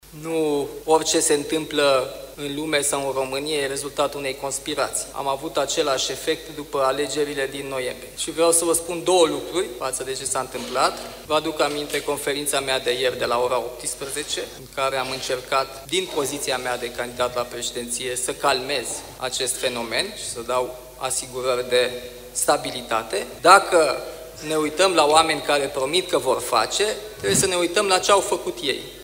George Simion și Nicușor Dan, cei doi candidați la președinție din turul doi al prezidențialelor, declarații la Congresul Blocului Național Sindical.